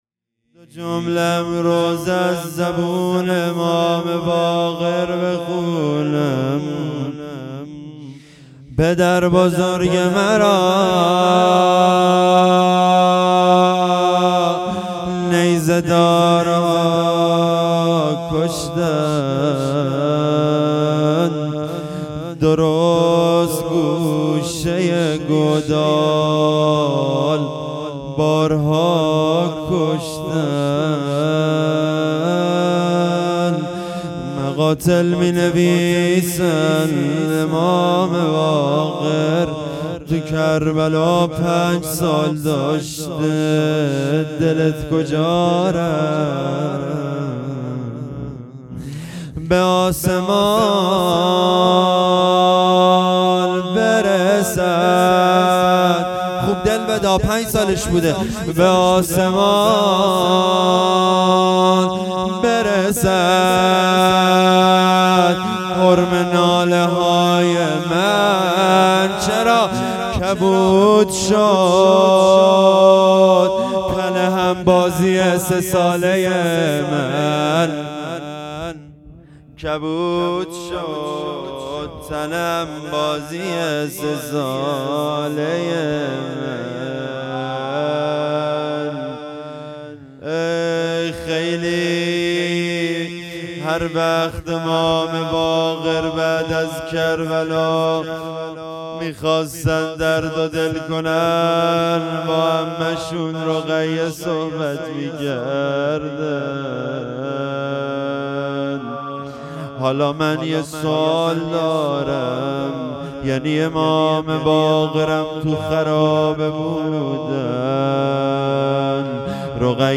هیئت هفتگی